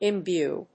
音節im・bue 発音記号・読み方
/ɪmbjúː(米国英語), ˌɪˈmbju:(英国英語)/